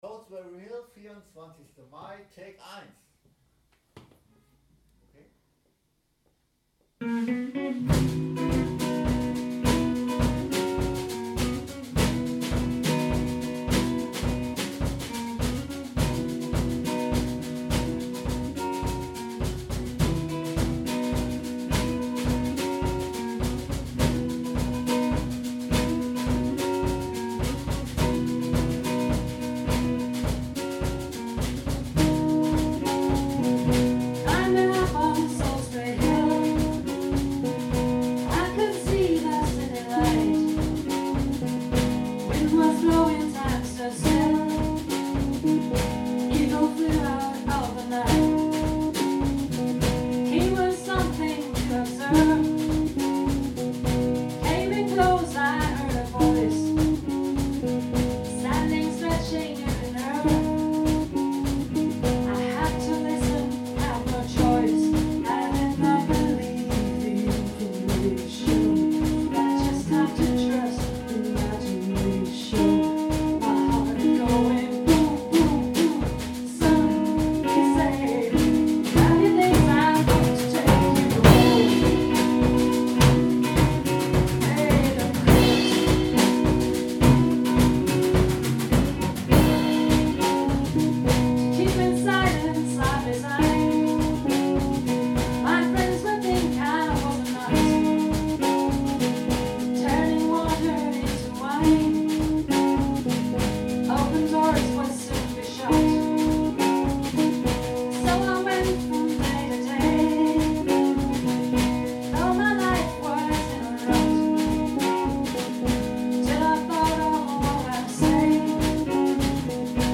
Version ohne Bass.